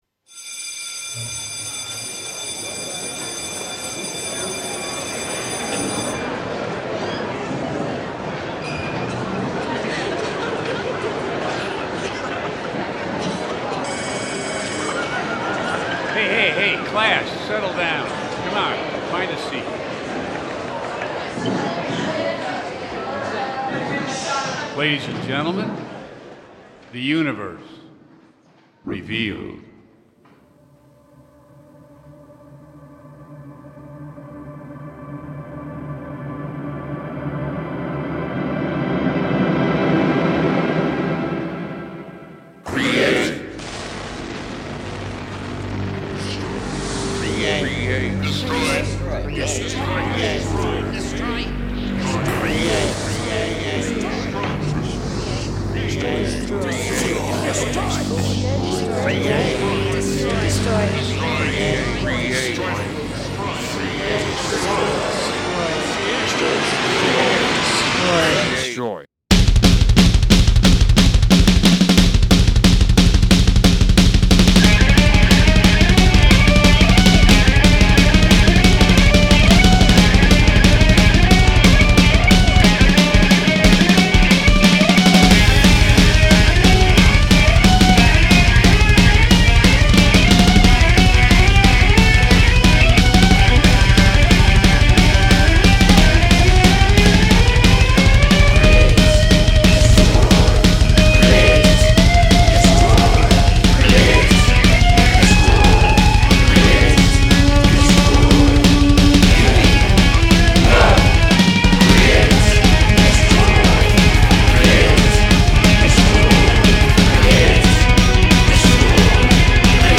The busy kicks and bass were hard to make play "nice" with each other.
Here is the opening "song" (more like an instrumental) that is purposefully a bit chaotic (hopefully in a fun way) and begins my 10 song album.
There are a lot of elements here, as this song could be thought of as more of a sound track then a song.